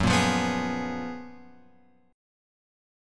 F# Major 11th